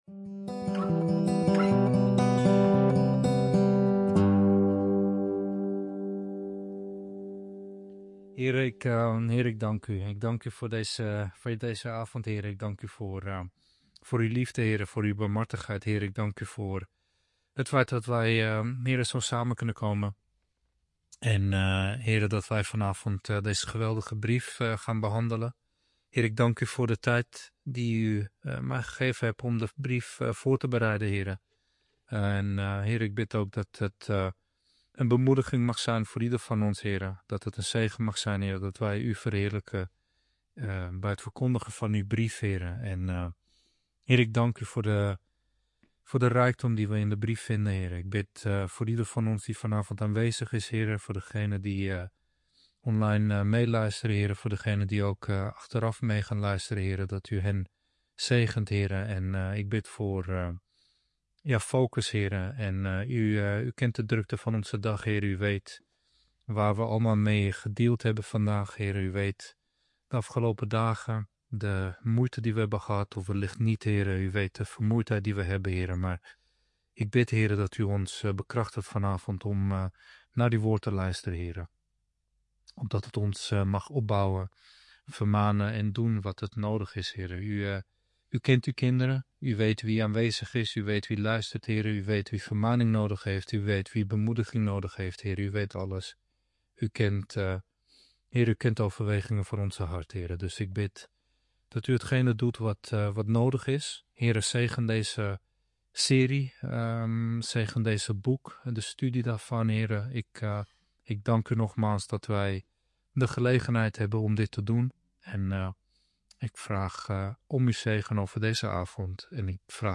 Verklarende prediking.